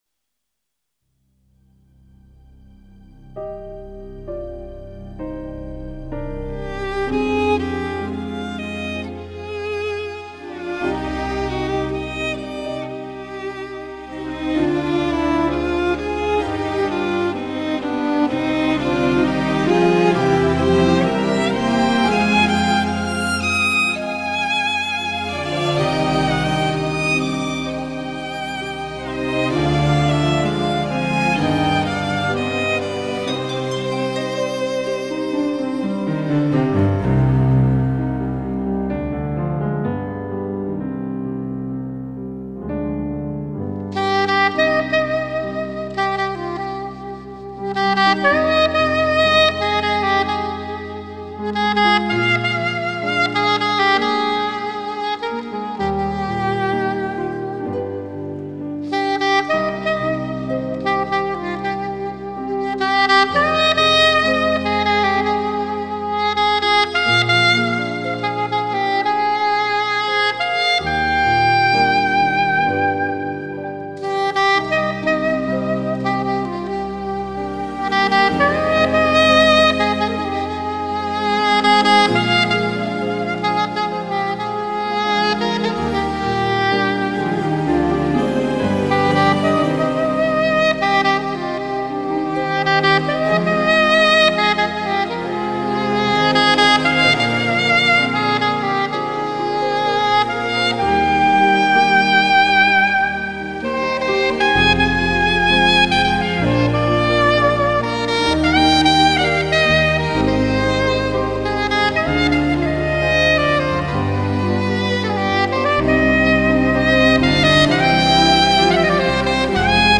Sax instr (закрыта)